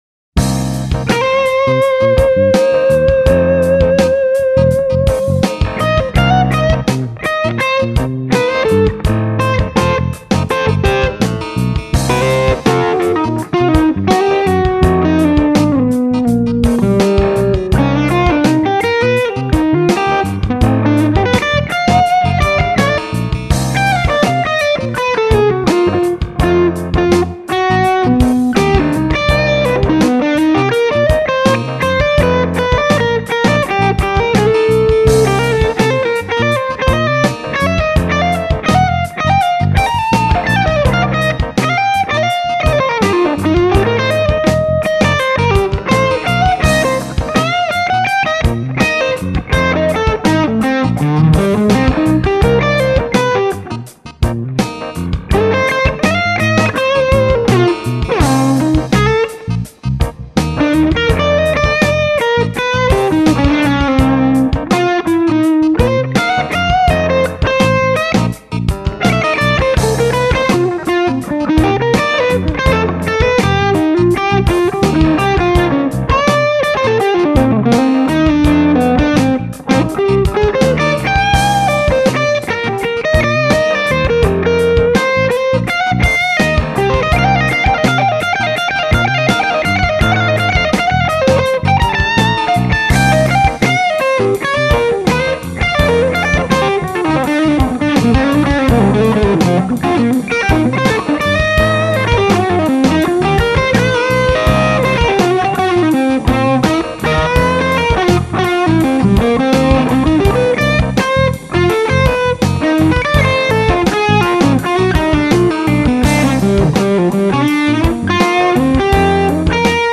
I put the Royer and Shure at the same depth here.